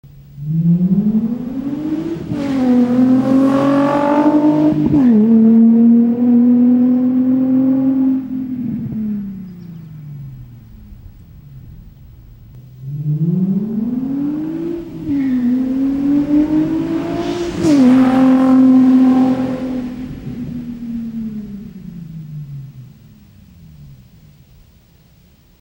RSR_DriveBy_NOsilencers.wav